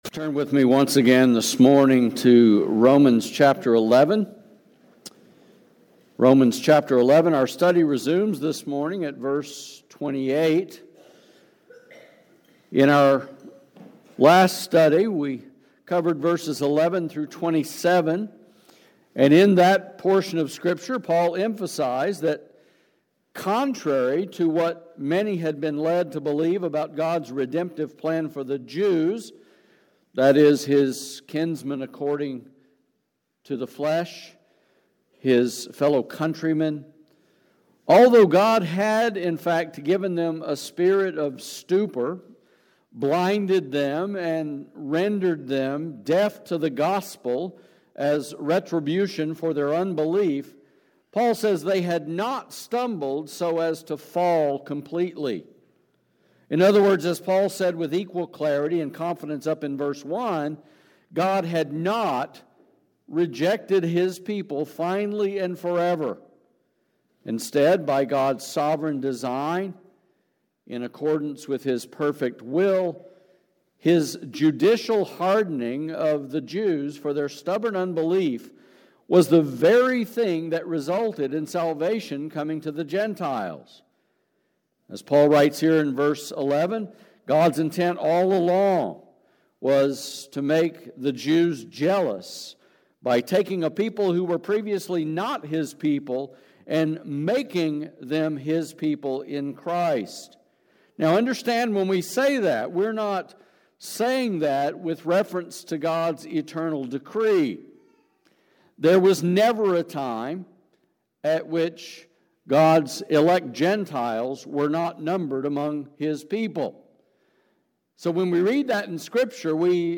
Sermons | Grace Baptist Church San Antonio